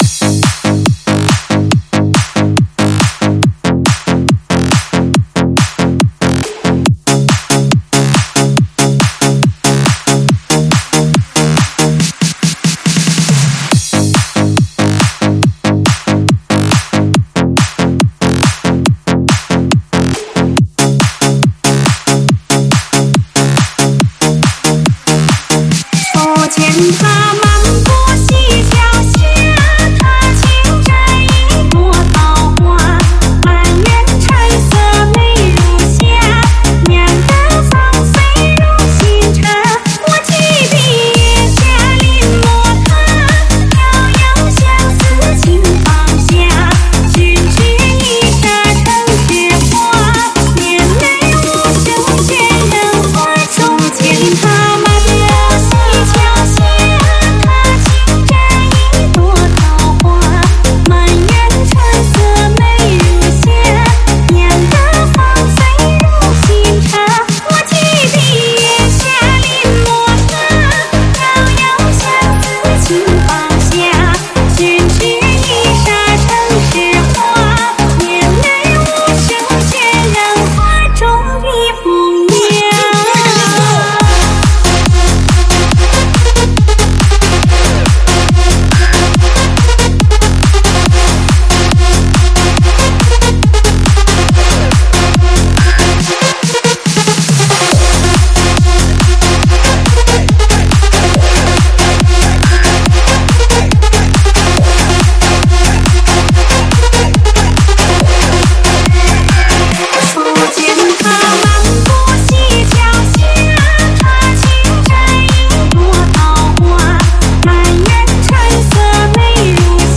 试听文件为低音质，下载后为无水印高音质文件 M币 4 超级会员 M币 2 购买下载 您当前未登录！